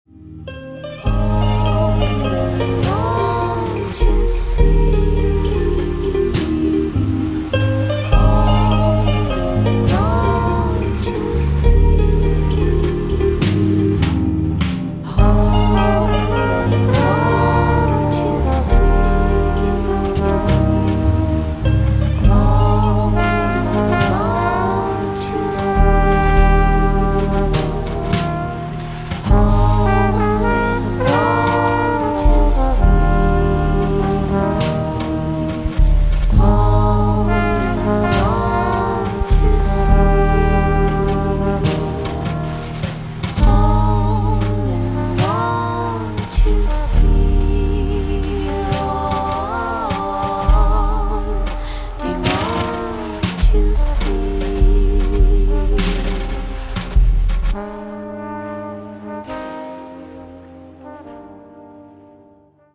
• Качество: 192, Stereo
спокойные
джаз
Nu Jazz